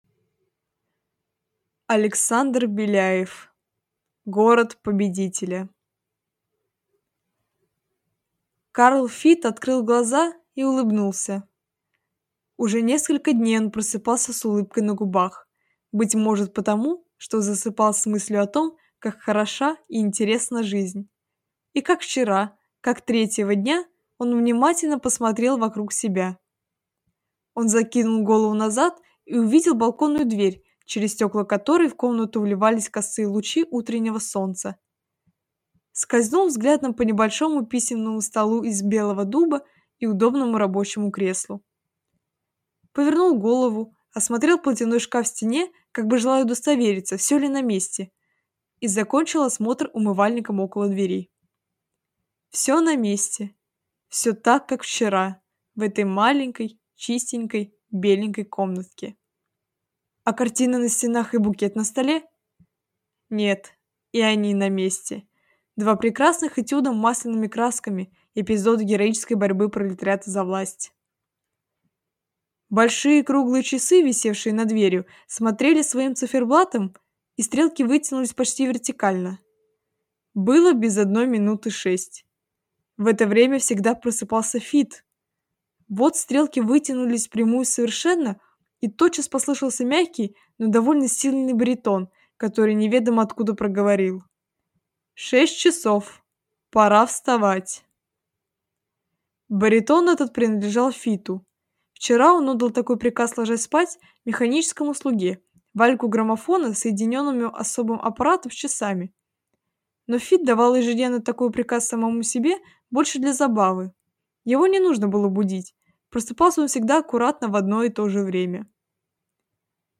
Аудиокнига Город победителя | Библиотека аудиокниг